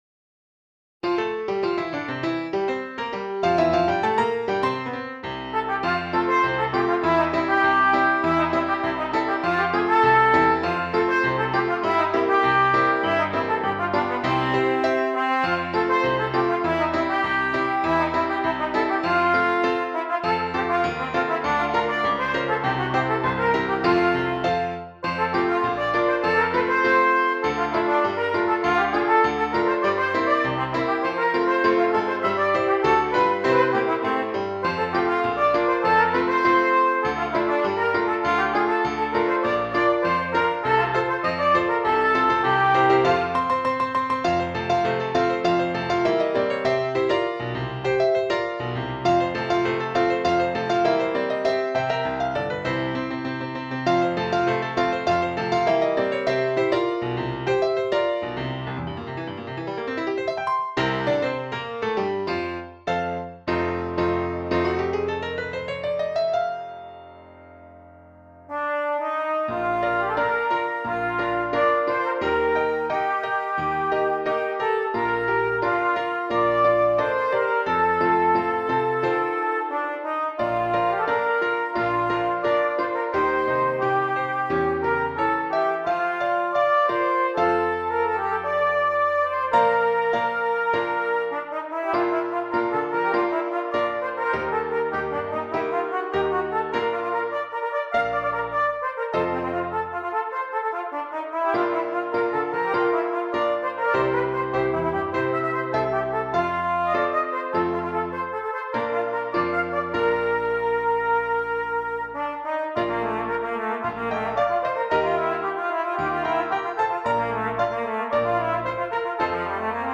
Trumpet and Keyboard
short fun tune for cornet and piano